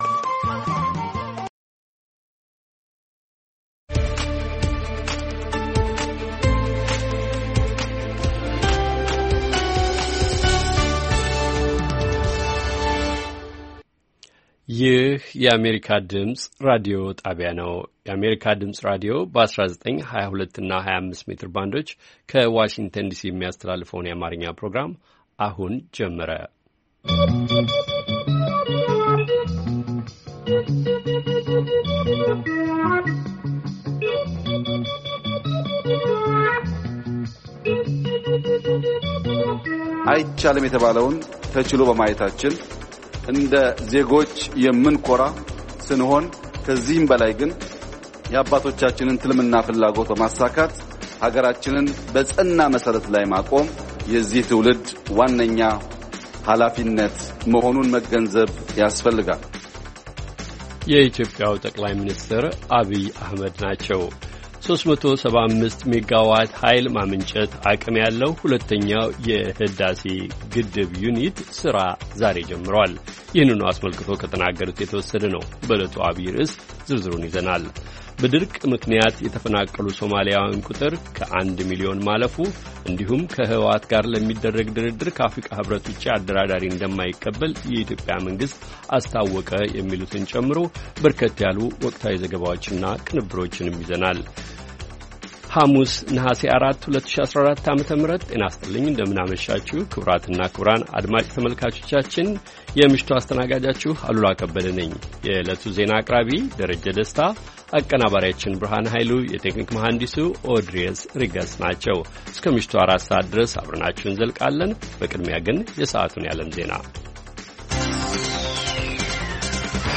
ሐሙስ፡-ከምሽቱ ሦስት ሰዓት የአማርኛ ዜና